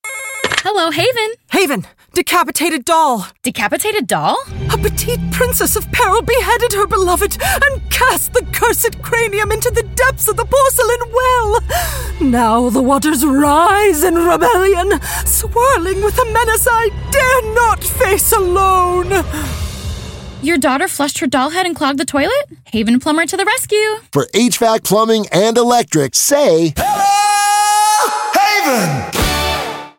RADIO: "DECAPITATED DOLL"